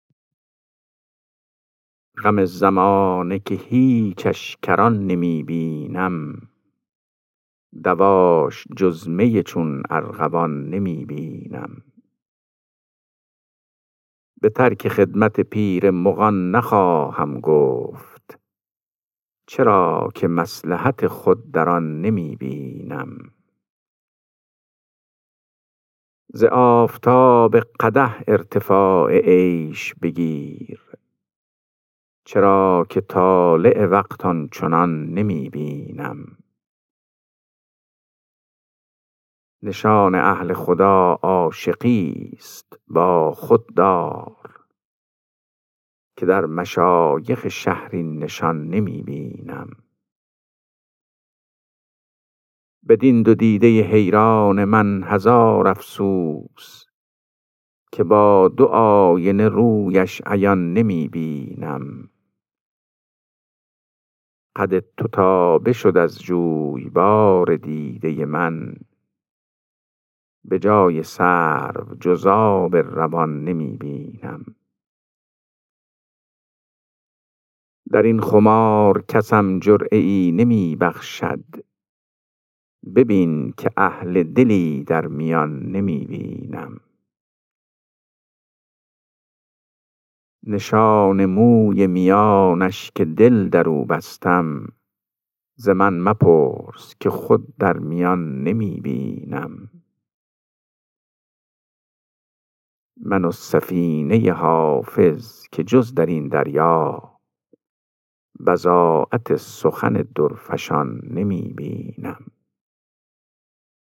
خوانش غزل شماره 358 دیوان حافظ